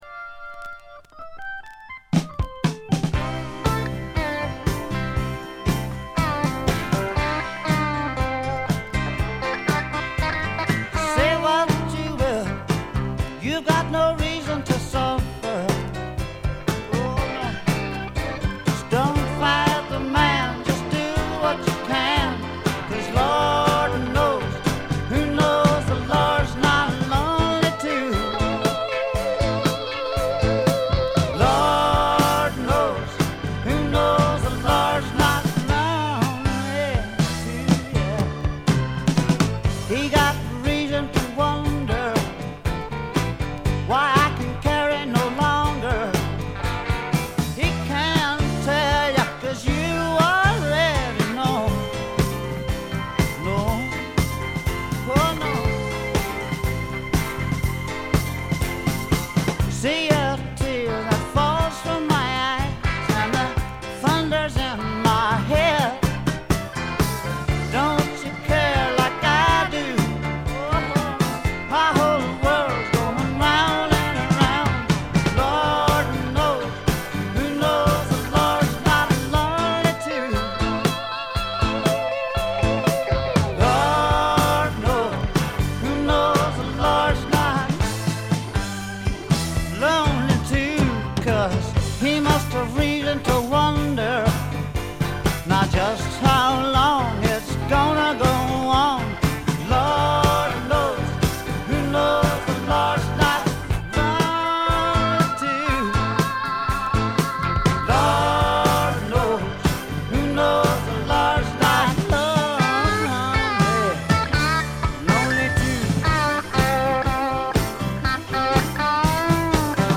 微細なノイズ感のみ。
ルーズでちょこっと調子っぱずれなあの愛すべきヴォーカルがまたよくて、本作の雰囲気を盛り上げています。
試聴曲は現品からの取り込み音源です。